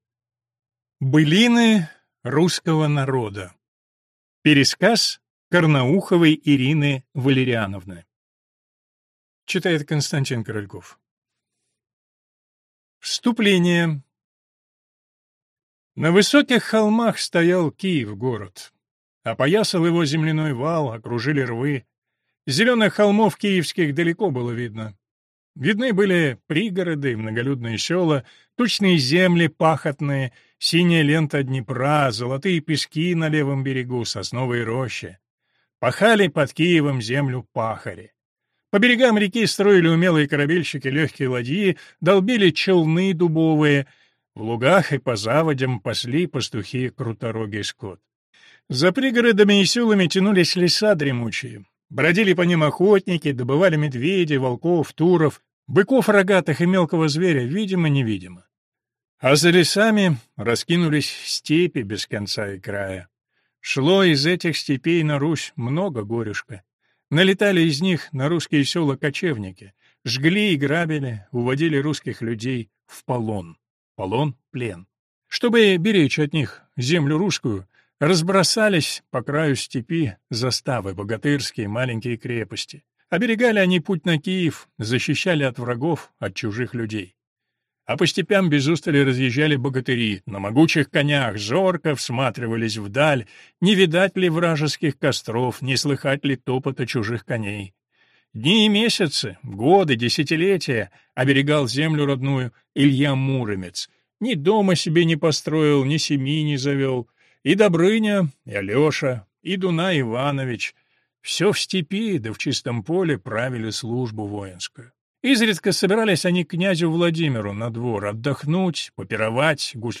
Аудиокнига Былины русского народа | Библиотека аудиокниг